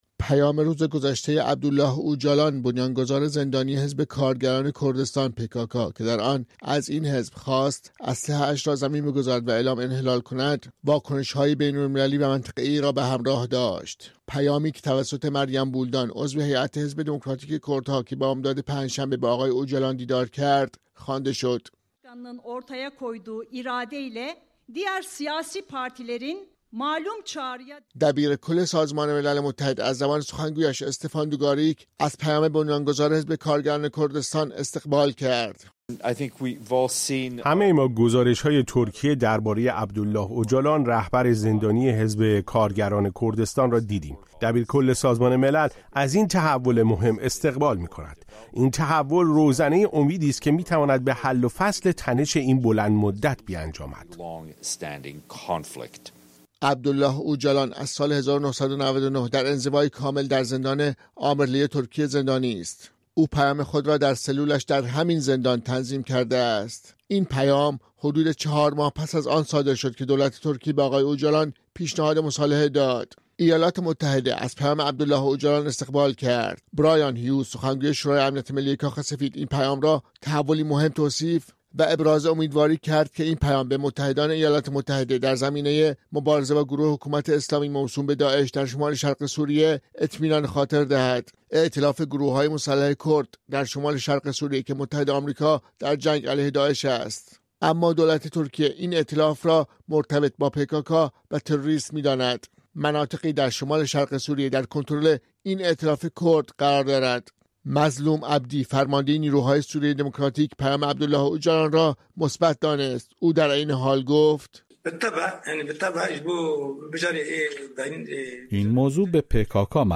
در گفت‌وگو با رادیو فردا